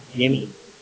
speech-commands